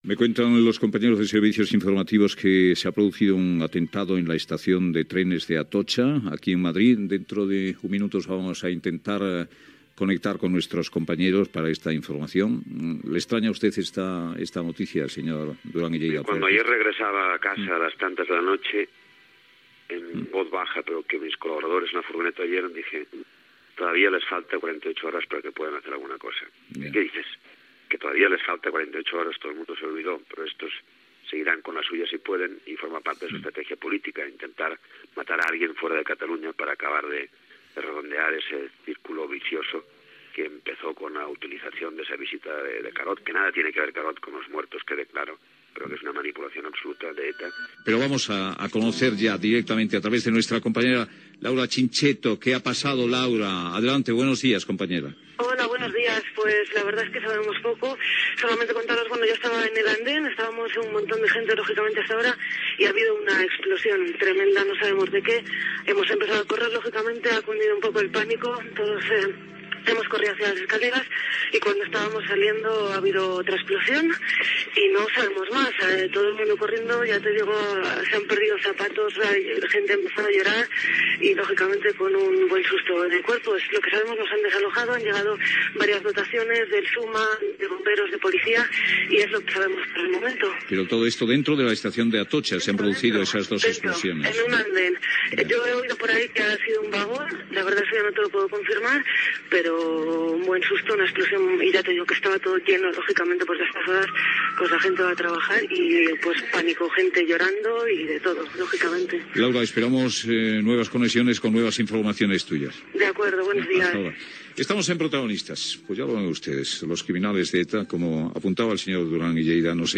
Fragment d'una entrevista al polític Josep Antoni Duran i Lleida. Fragments de les primeres informacions i comentaris sobre els atemptats del 14 de març de 2004 a tres trens de rodalia de Madrid.
Entrevista a l'alcalde de Madrid Ruiz Gallardón
Info-entreteniment